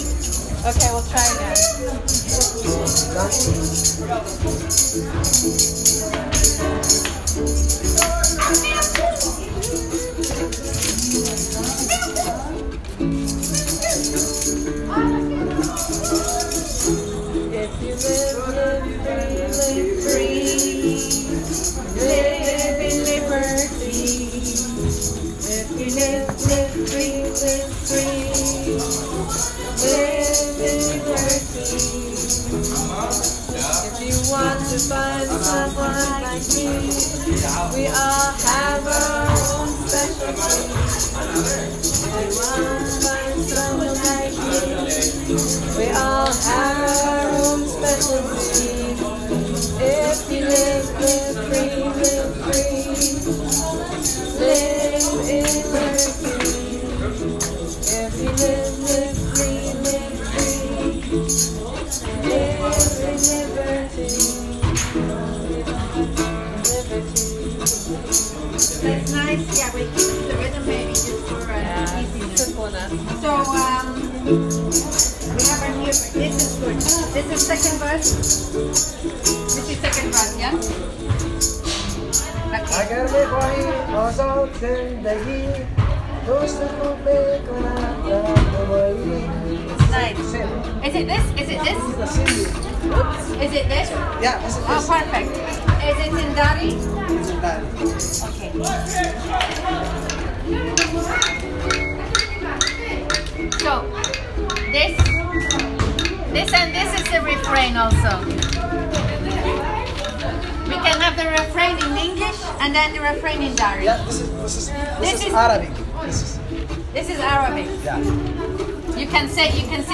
These are recordings from the center that show the process of writing the song:
liberty-singthrough-and-adding-languages.m4a